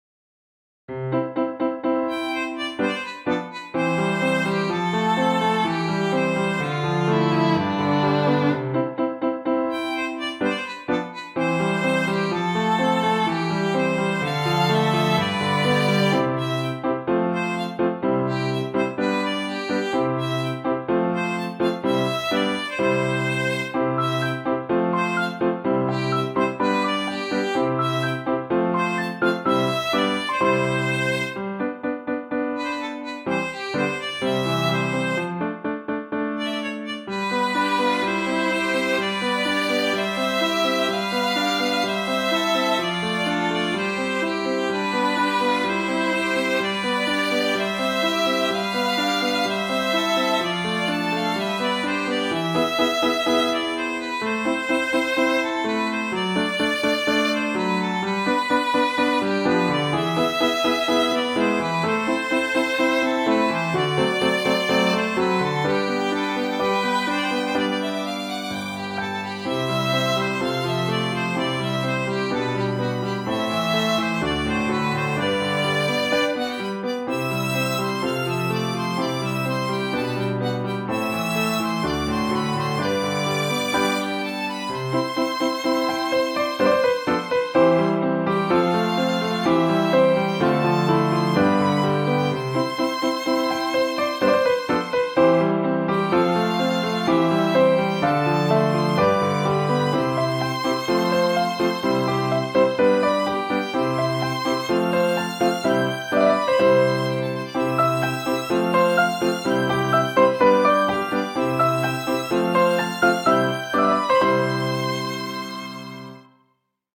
ogg(L) 明るい 優雅 バイオリン ピアノ
流麗なバイオリンとピアノ伴奏。